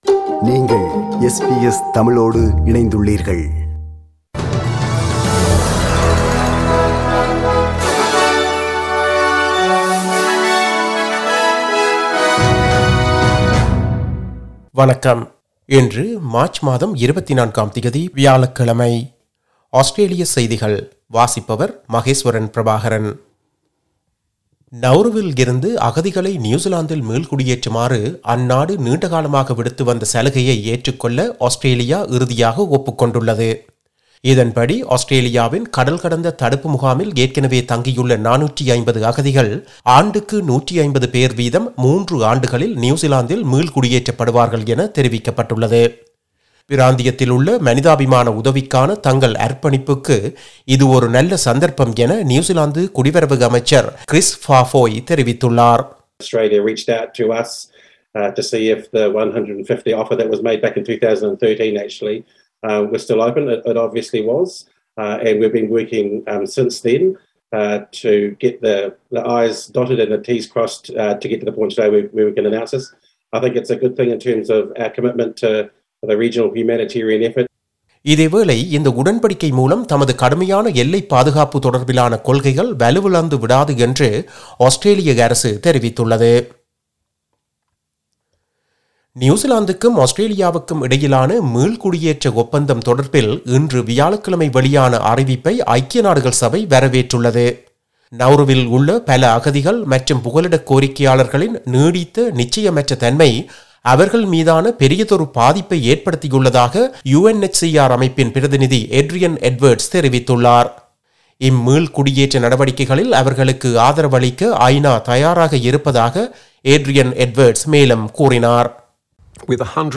Australian news bulletin for Thursday 24 March 2022.